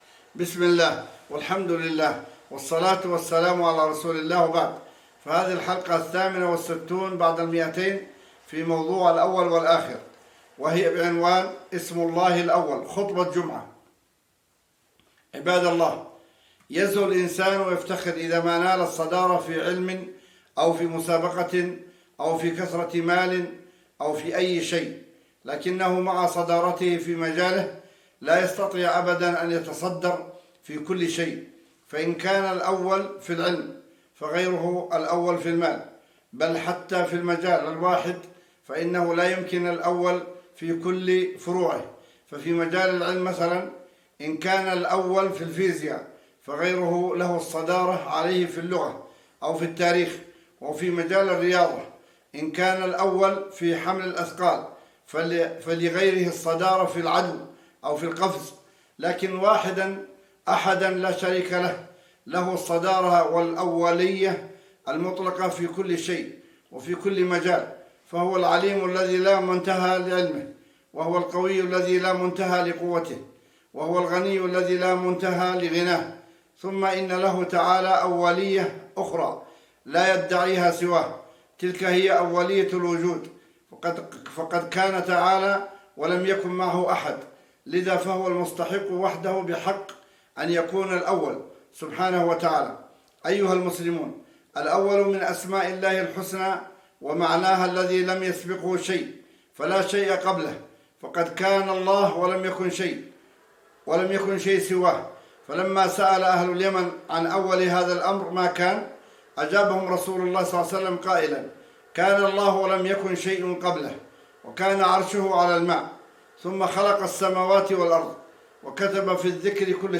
بعنوان :* اسم الله الأول ( خطبة جمعة )